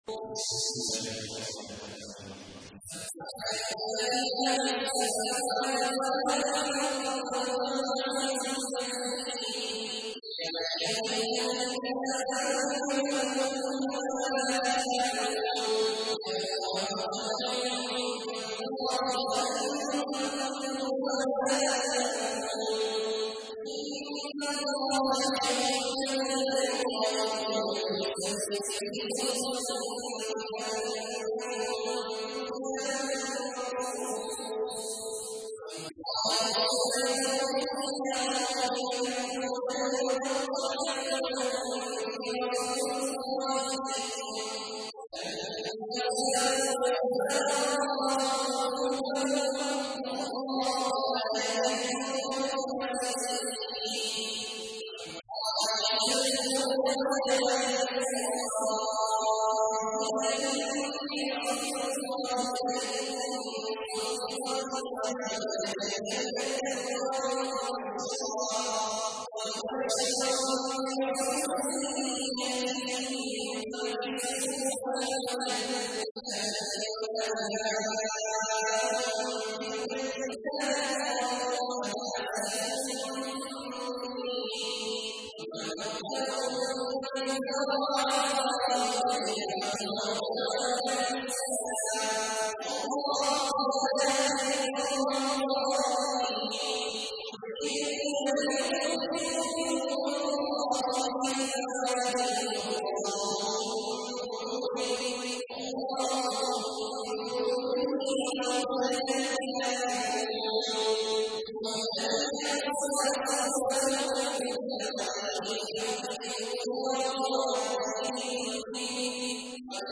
تحميل : 61. سورة الصف / القارئ عبد الله عواد الجهني / القرآن الكريم / موقع يا حسين